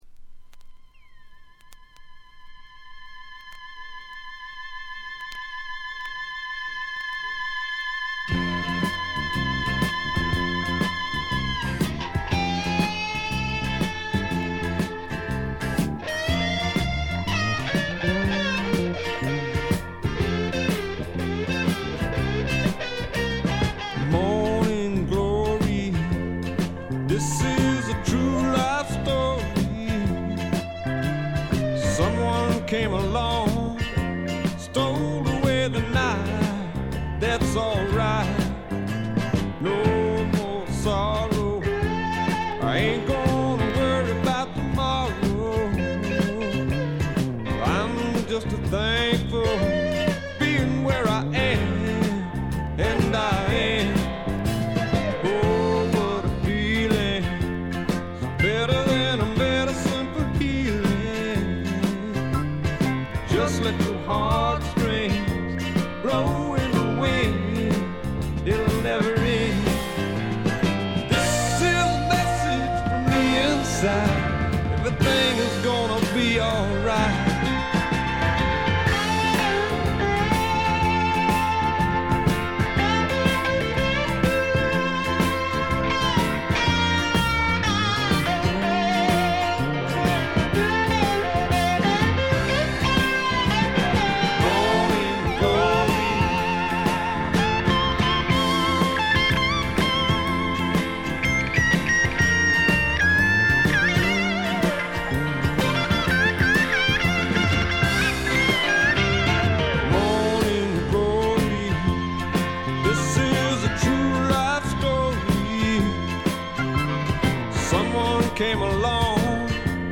ところどころでチリプチ、プツ音少し。
フリーソウル的なポップ感覚が心地よいです。
この人はやっぱトレードマークのスライド・ギターが素晴らしいですね。
試聴曲は現品からの取り込み音源です。